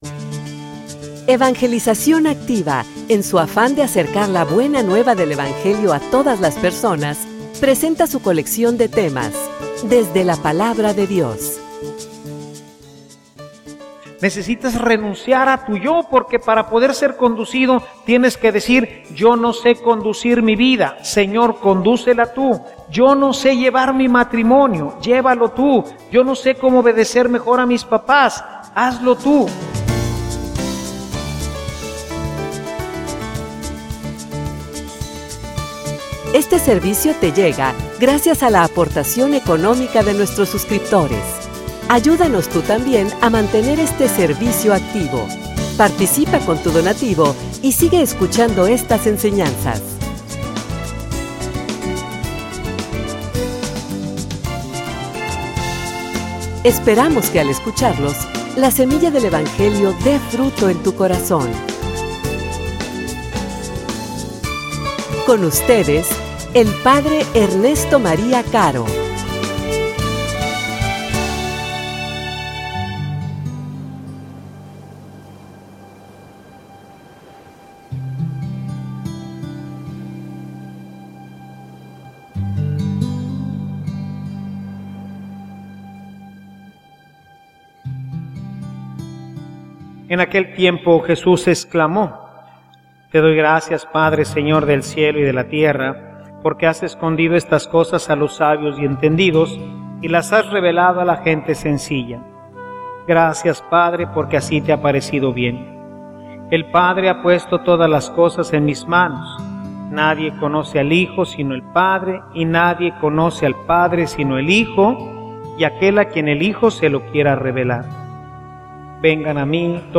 homilia_Aprendan_de_mi.mp3